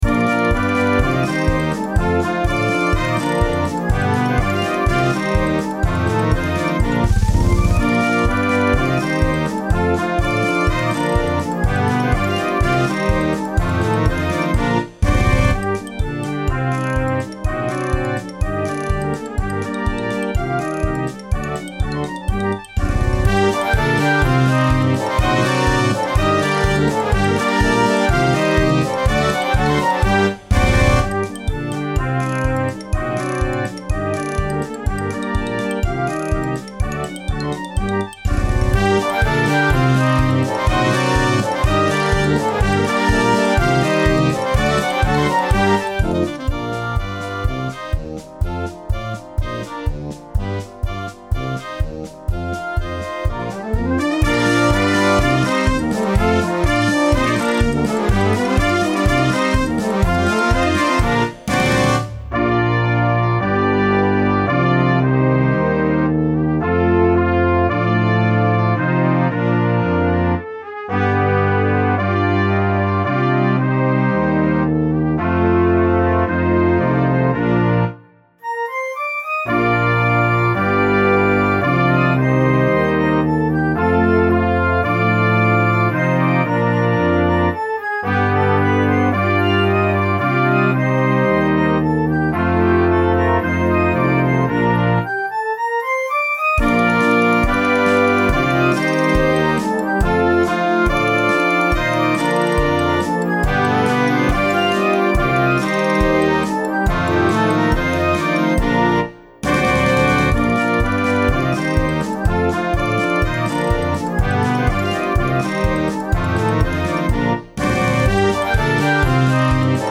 Russisches Volkslied
Blasorchester